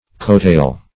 \coat"tail\